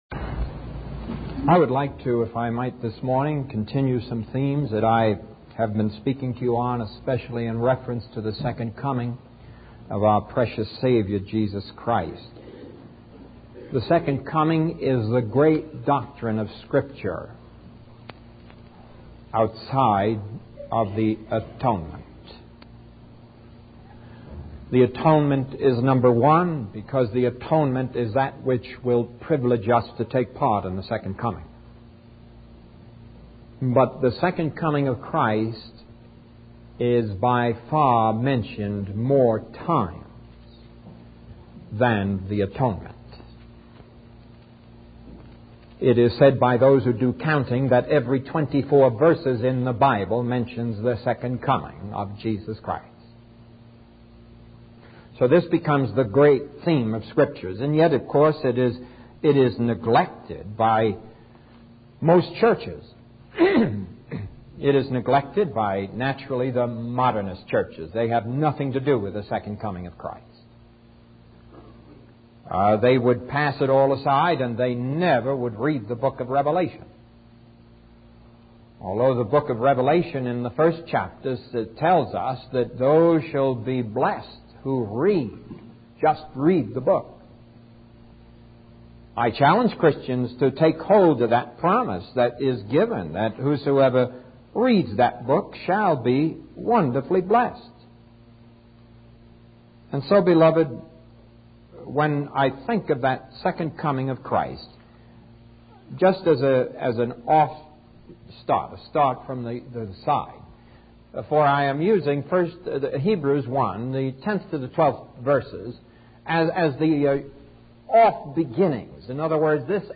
In this sermon, the preacher emphasizes the importance of living a godly life in the present time. He urges believers to separate themselves from worldly influences, including alcohol. The preacher expresses concern that Christians are becoming complacent and getting involved in sinful activities.